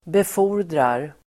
Uttal: [bef'o:r_drar]